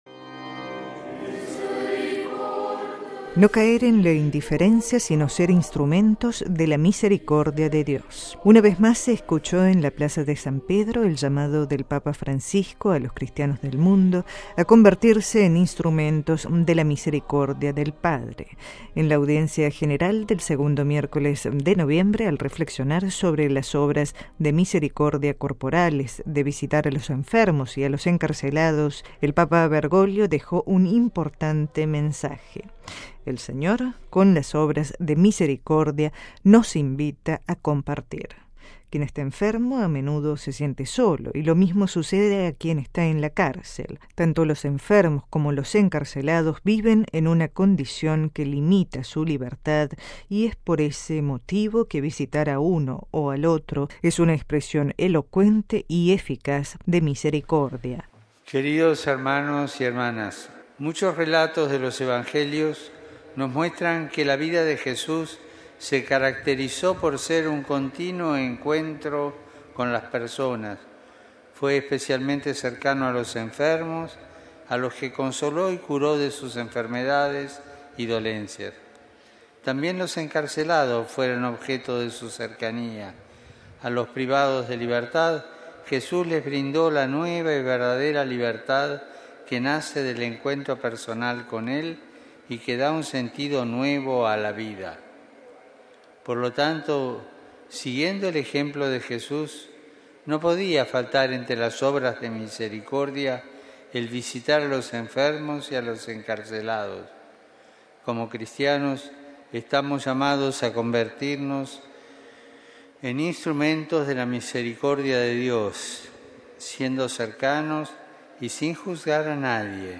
No caer en la indiferencia, ser instrumentos de la misericordia de Dios. Exhortación del Papa
En la audiencia general del segundo miércoles de noviembre, al reflexionar sobre las obras de misericordia corporales de visitar a los enfermos y a los encarcelados, el Papa Bergoglio dejó un importante mensaje: el Señor, con las obras de misericordia nos invita a compartir.